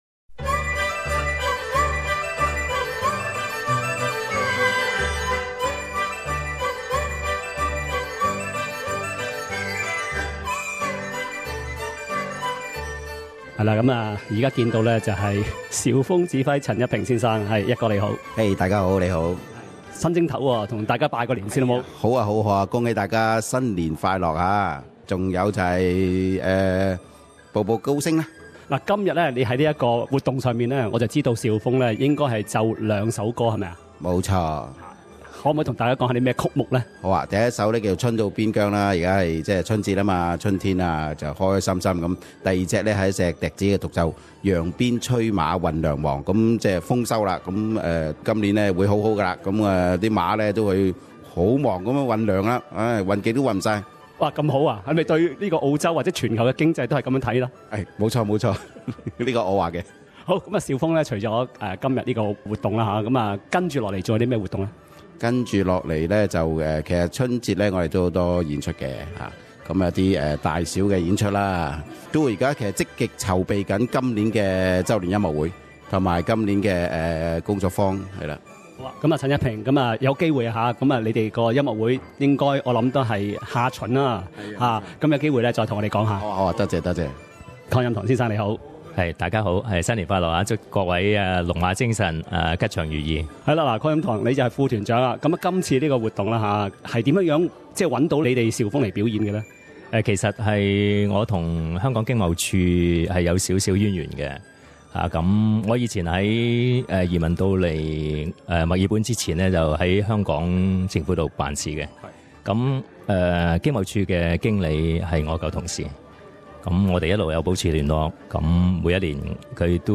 香港駐悉尼經濟貿易辦事處最近在墨爾本舉辦了一個春節聚會，場面熱鬧。